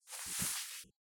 remove_mask.mp3